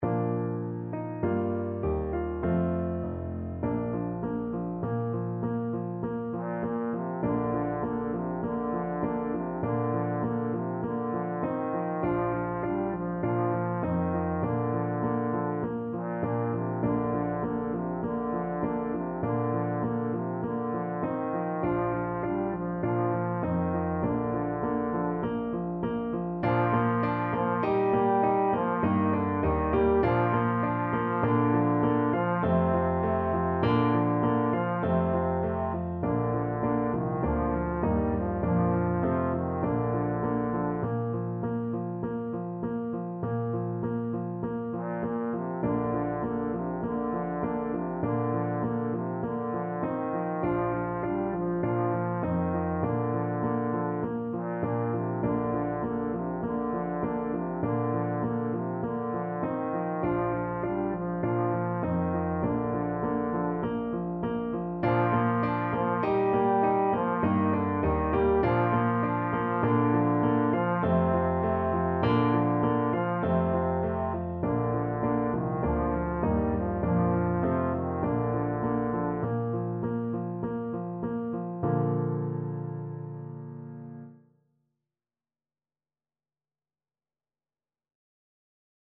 Christmas Christmas Trombone Sheet Music Es ist fur uns eine Zeit angekommen
Trombone
Traditional Music of unknown author.
4/4 (View more 4/4 Music)
Bb major (Sounding Pitch) (View more Bb major Music for Trombone )
Moderato